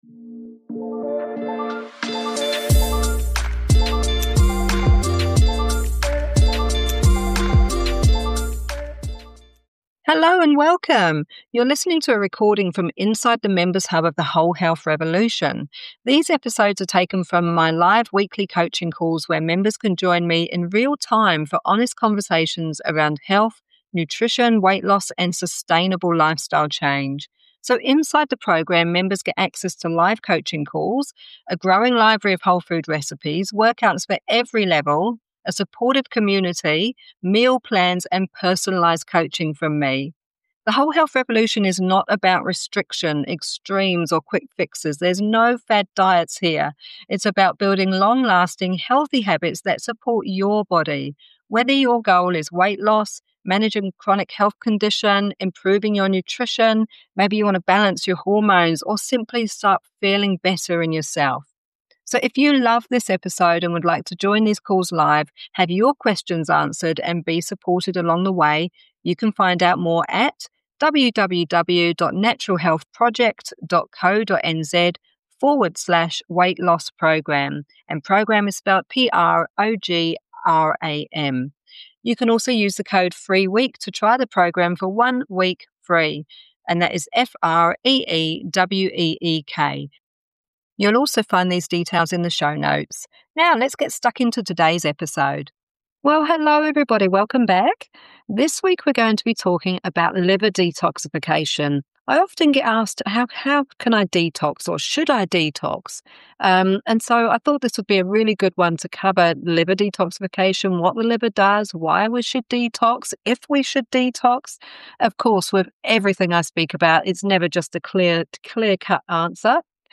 Public episodes are edited for listening, while the full live experience remains exclusive to members.